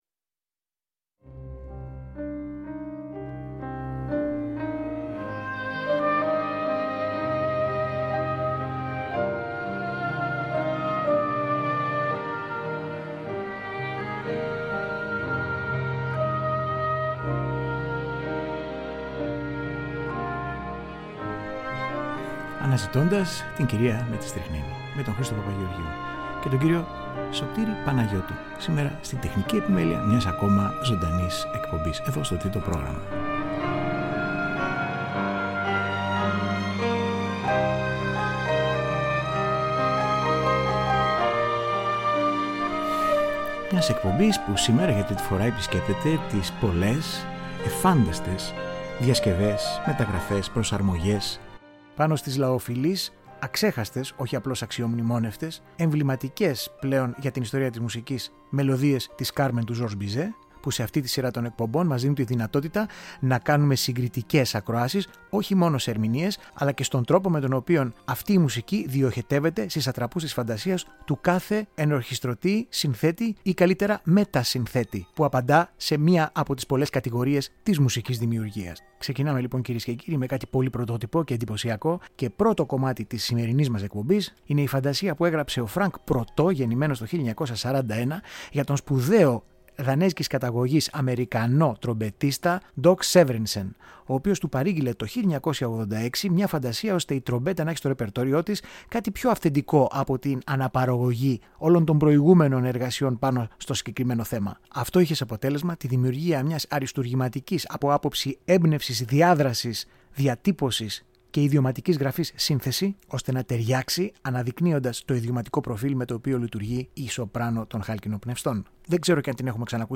Μουσικες Διασκευες